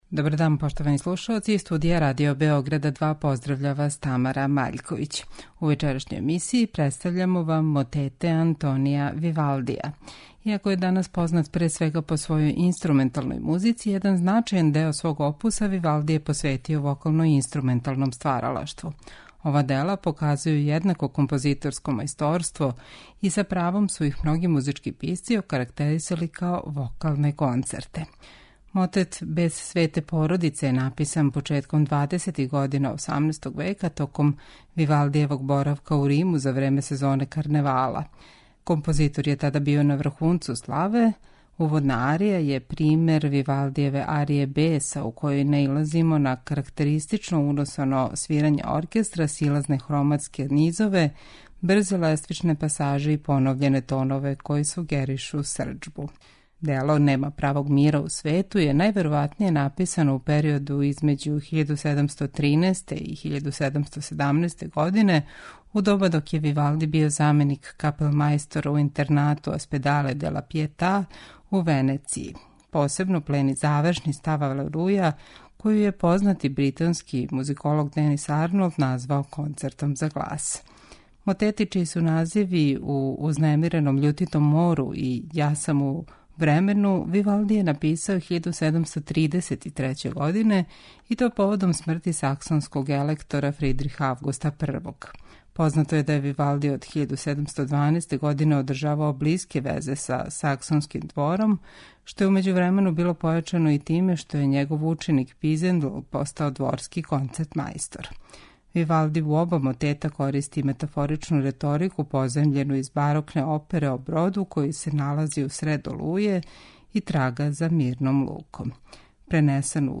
У данашњој емисији Висине слушаћете мотете Антонија Вивалдија.